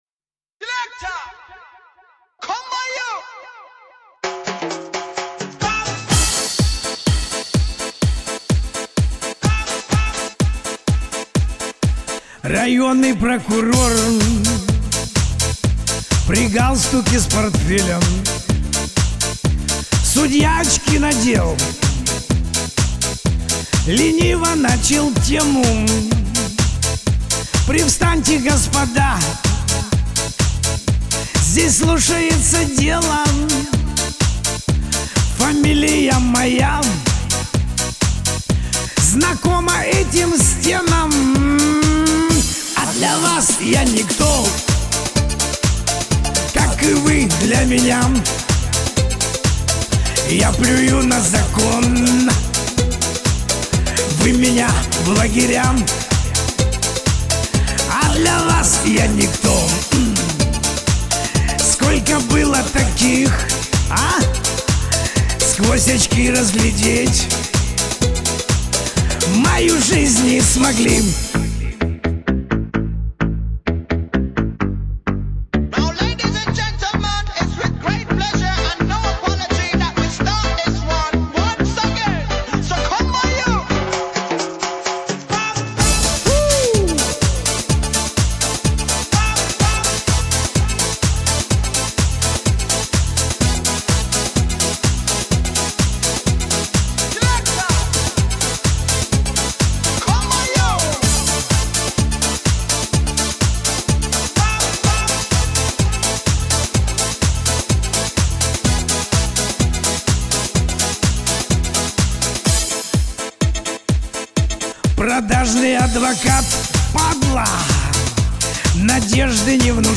тож не плохая перепевка..приколист блинннн